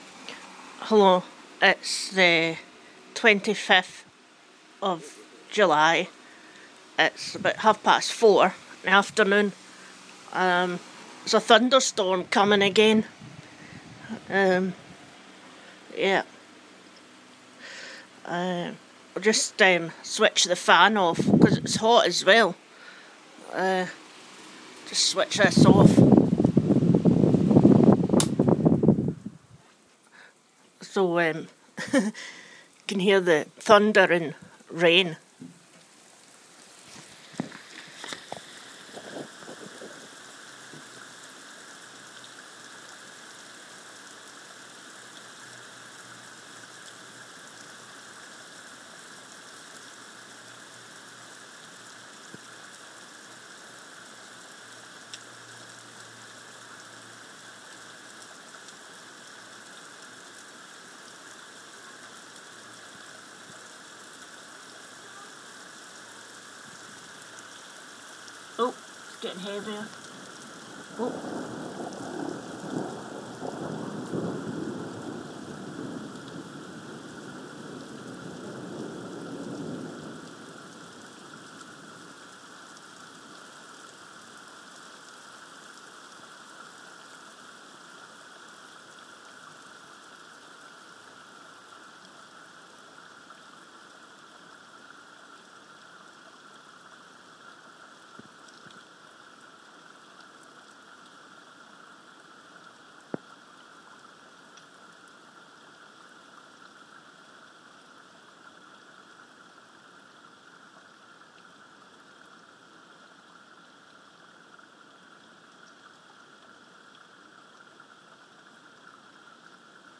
thunder storm 2